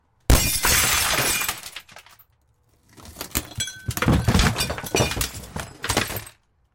随机 " 用斧头打破窗户 玻璃在拖车上破碎
描述：窗口休息与axes玻璃粉碎在trailer.wav
Tag: 拖车 斧头 窗口 玻璃 断裂 粉碎